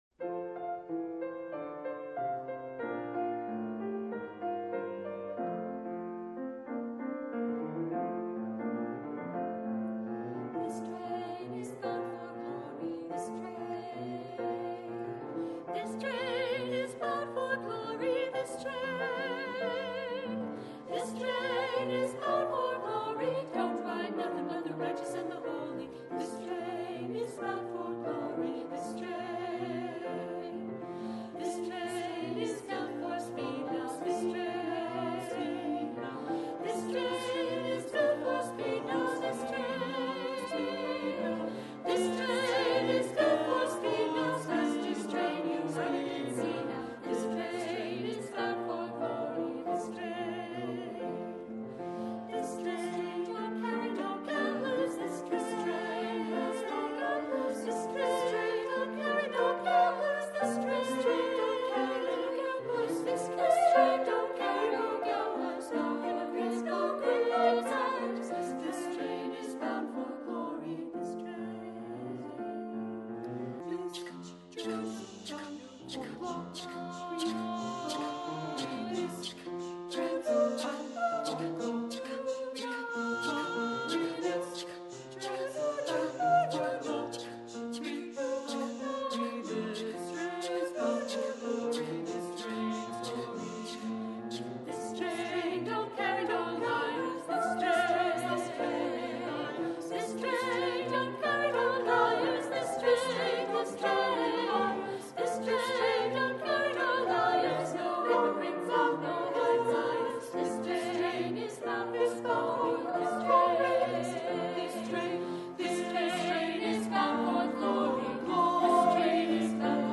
Accompaniment:      Piano